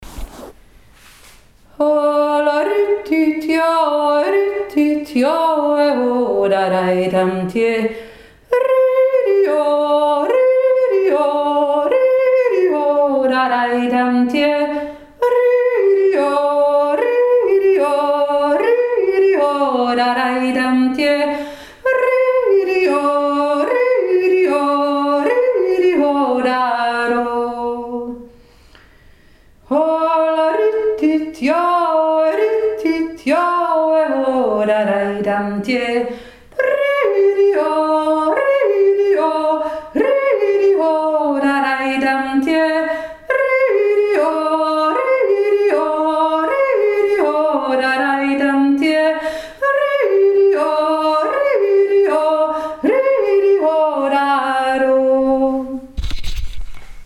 Goldegg jodelt 2023
1. Stimme